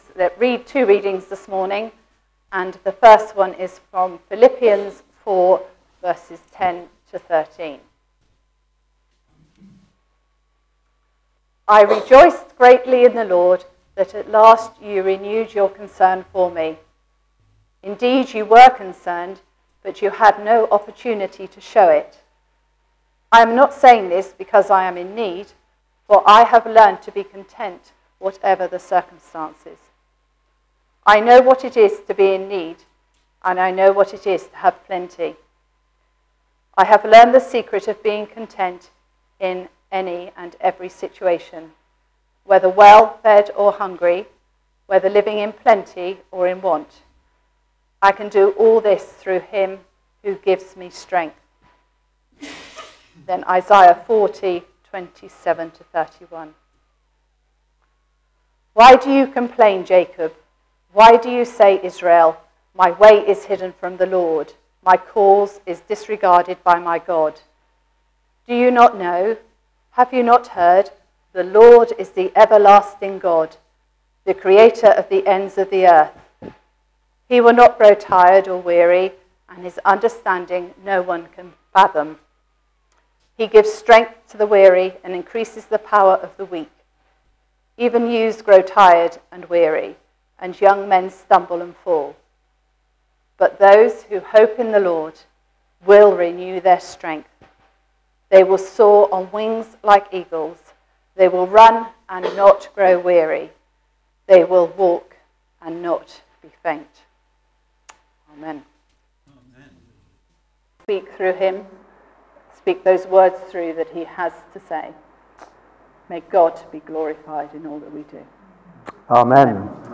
Sunday sermons from Wilton Baptist Church - The Church in the Square